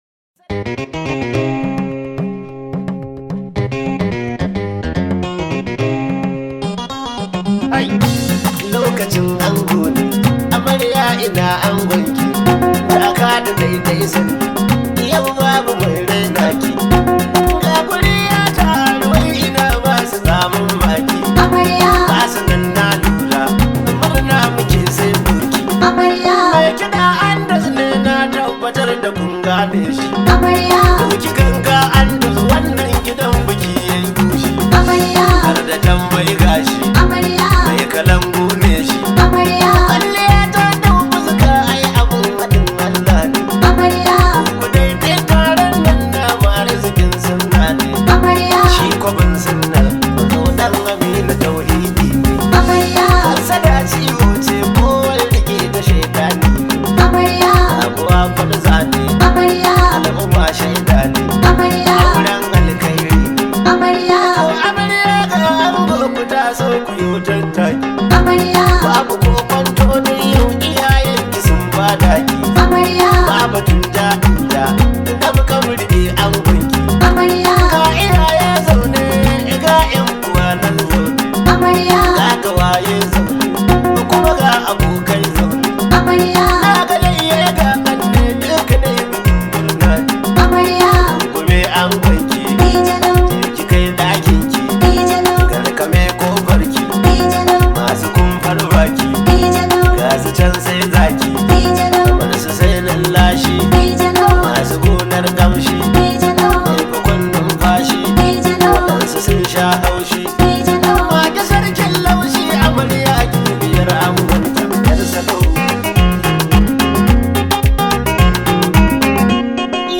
This high vibe hausa song